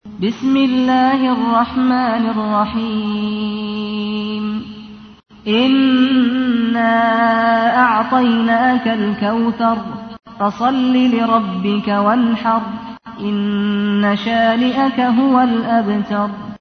تحميل : 108. سورة الكوثر / القارئ سعد الغامدي / القرآن الكريم / موقع يا حسين